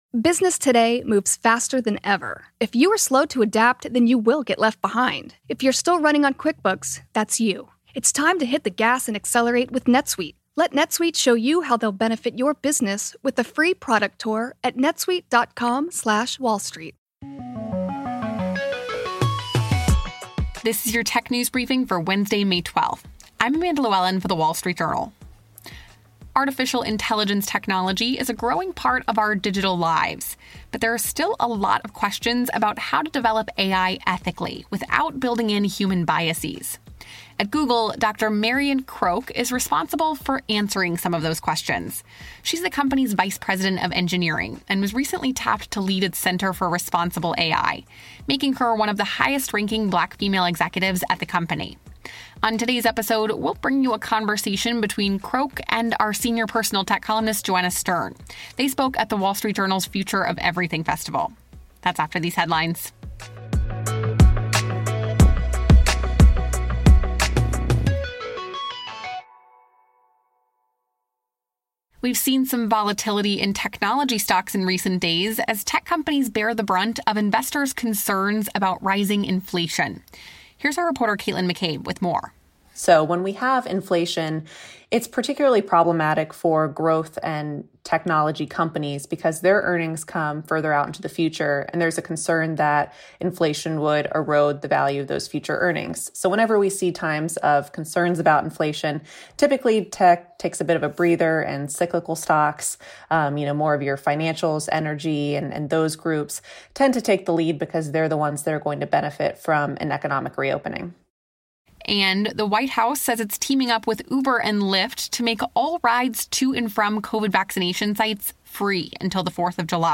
This week The Wall Street Journal is sitting down with industry movers and shakers for our Future of Everything Festival. Senior Personal Tech Columnist Joanna Stern interviews Dr. Marian Croak, Google's engineering vice president and head of the tech giant's responsible AI team, about the ethical development of artificial-intelligence technology.